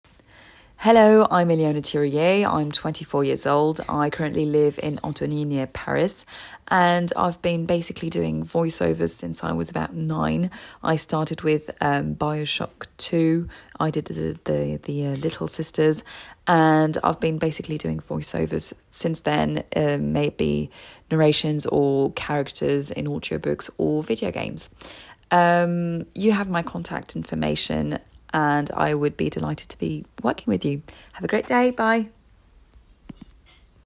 English Presentation - UK ACCENT